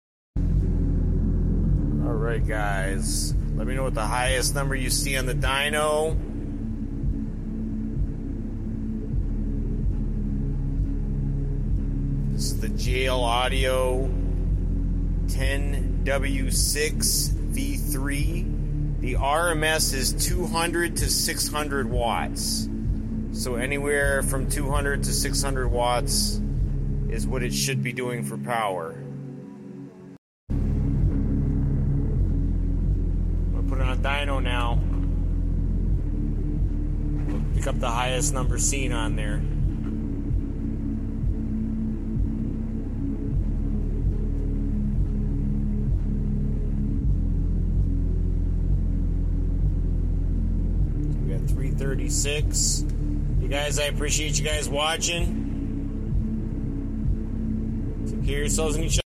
JL Audio HO110 W6V3 Sub Flex sound effects free download